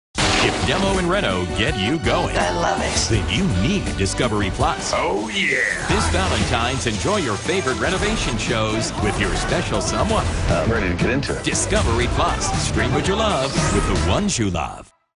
Joe CiprianoDemos And Renos on Discovery+Promos Download This Spot